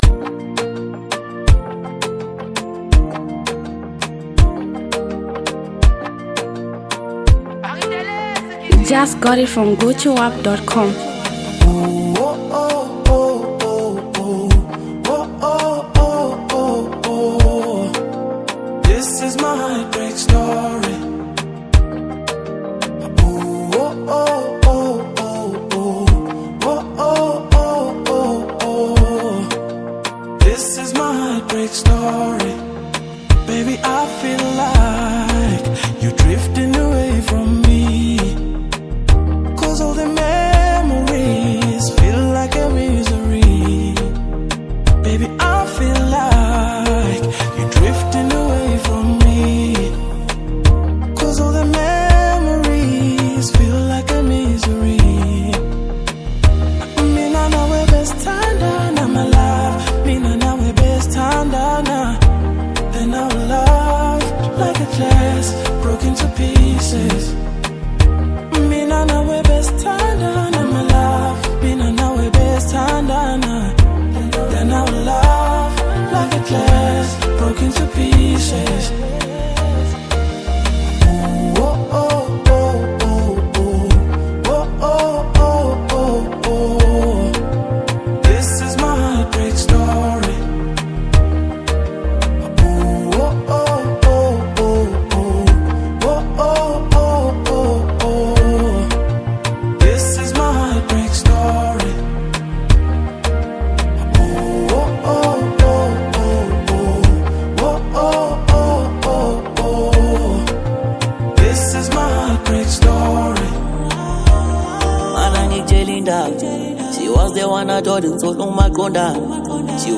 Afro-Soul, Contemporary R&B, Hip-Hop
soulful vocals